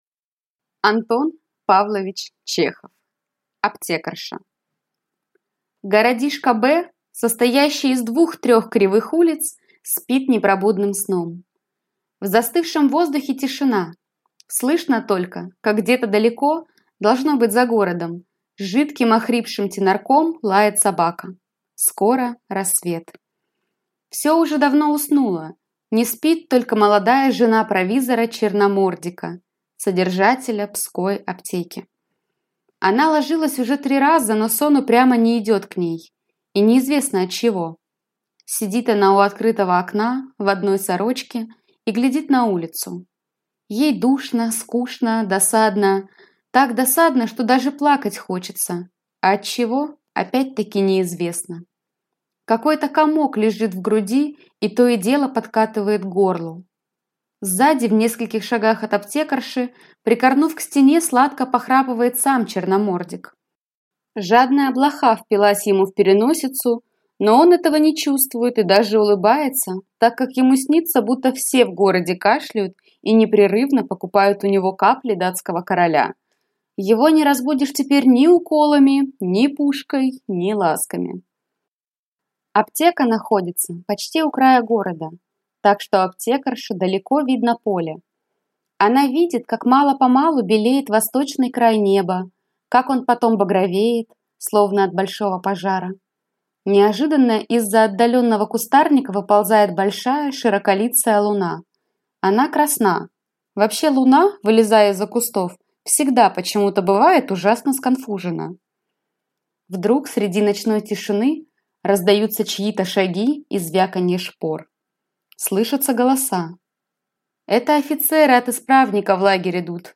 Аудиокнига Аптекарша | Библиотека аудиокниг